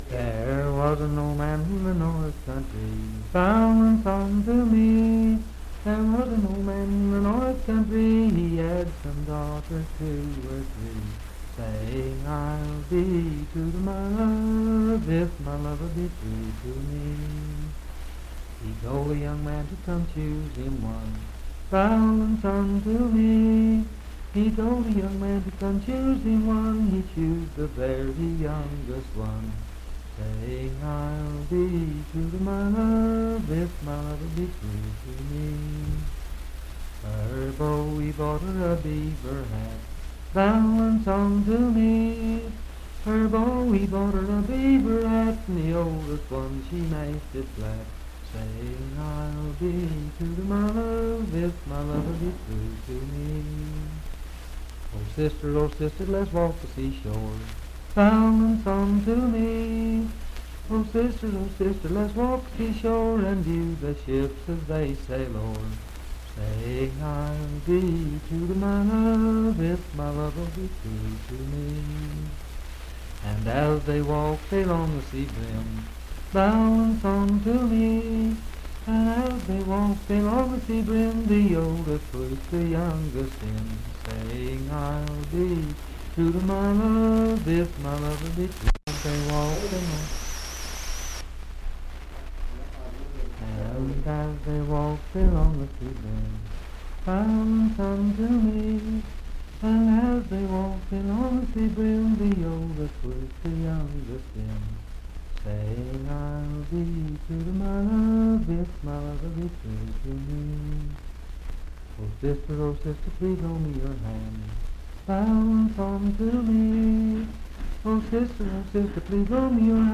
Unaccompanied vocal music
Verse-refrain 12(6w/R).
Voice (sung)
Marlinton (W. Va.), Pocahontas County (W. Va.)